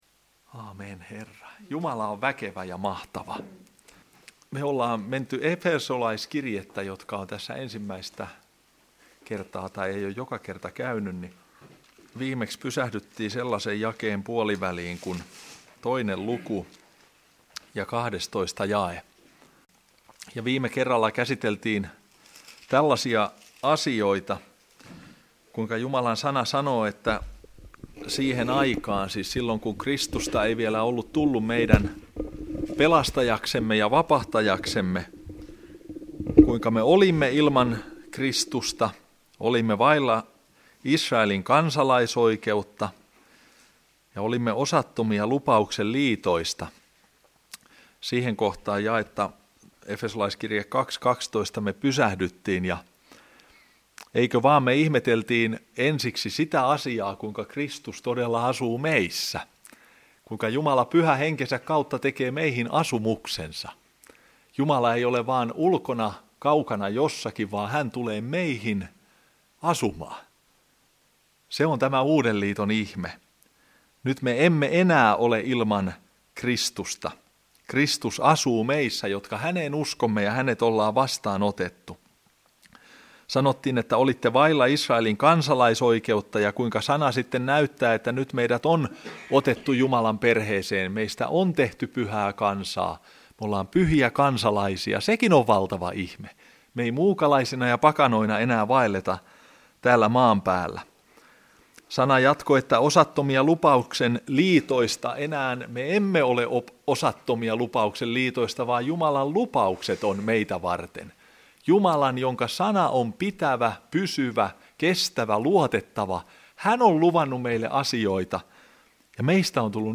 Tästä voit kuunnella opetuslapseuskoulun tunteja.